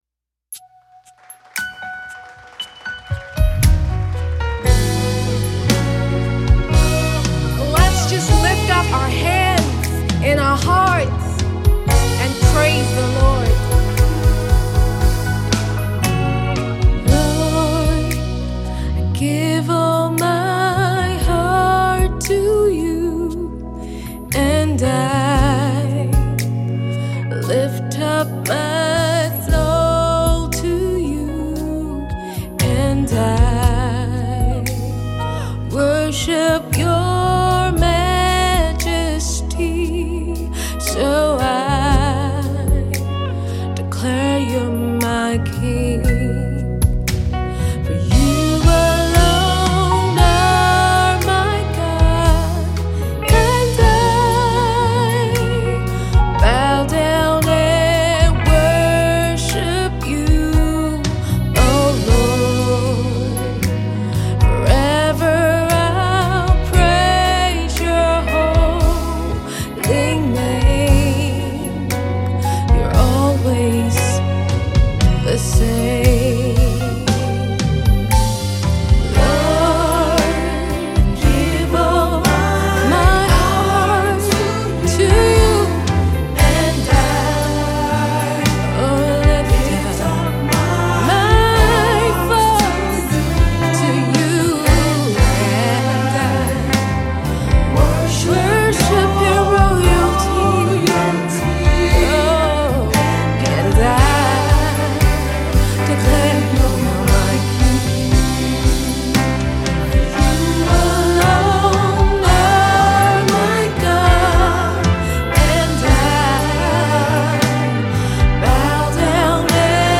The new worship piece carries a tone of reckless abandonment